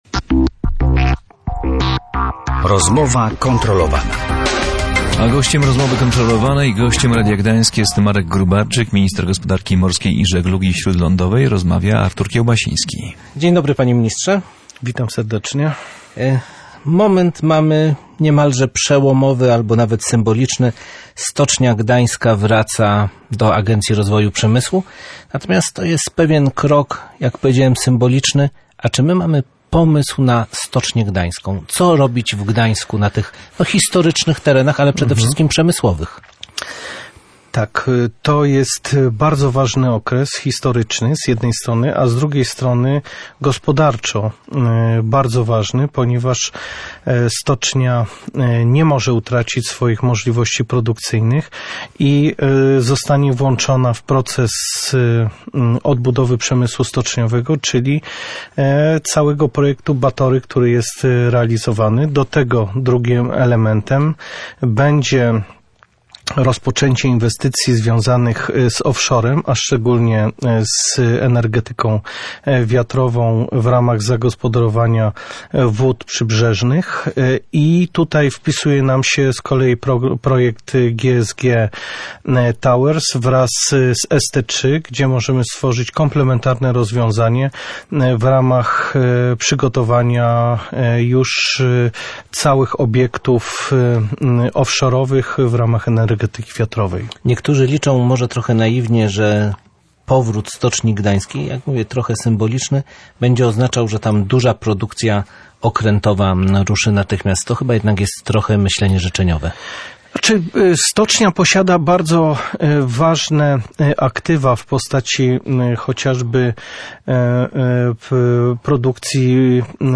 Co teraz będzie ze Stocznią Gdańsk, a także – czy zagrożenie związane z wrakiem Frankena jest realne? Między innymi na te pytania odpowiadał minister gospodarki wodnej i żeglugi śródlądowej, który był gościem Rozmowy Kontrolowanej.